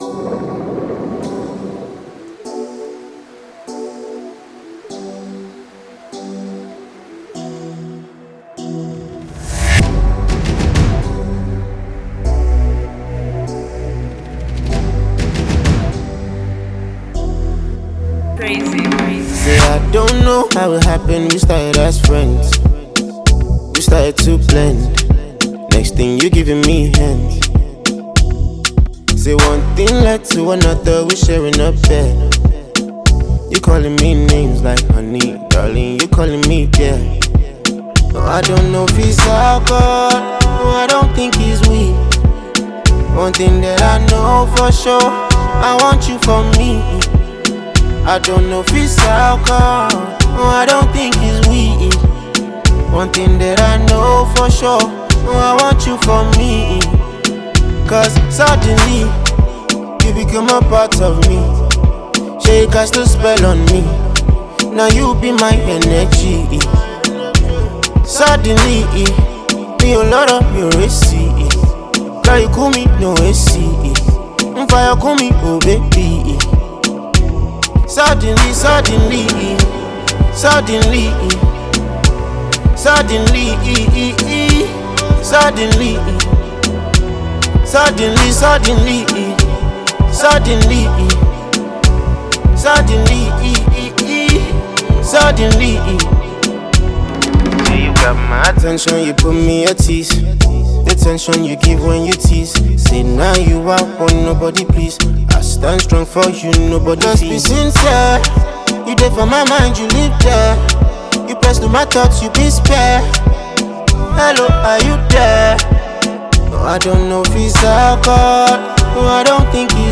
mid-tempo track